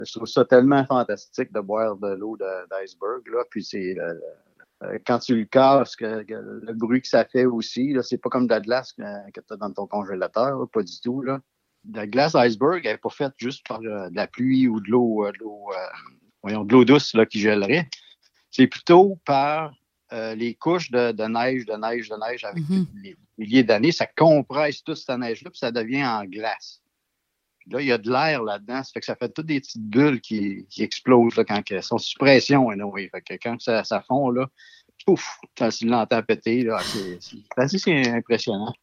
Un plaisir qui a de quoi devenir contagieux tellement il en parle avec un bonheur dans la voix.